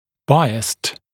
[‘baɪəst][‘байэст]предубежденный, несправедливый, предвзятый, пристрастный